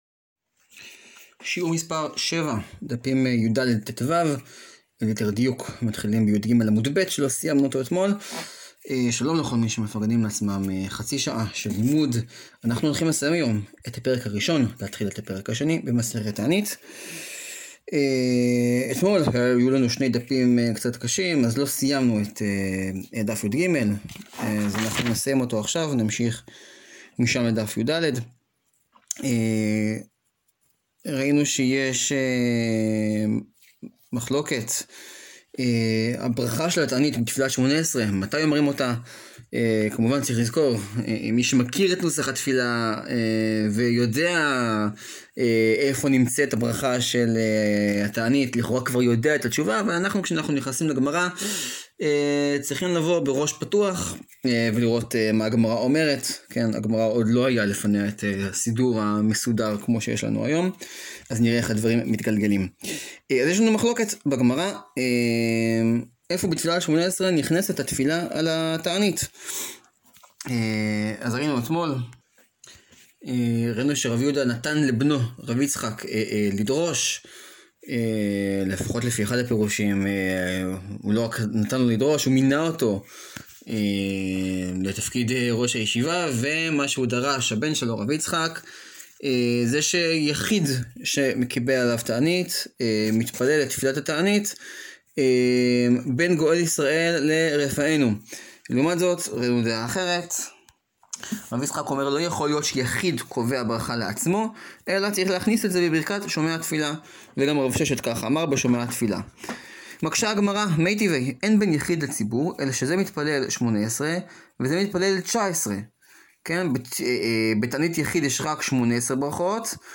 שיעור 7 להאזנה: מסכת תענית, דפים יד-טו.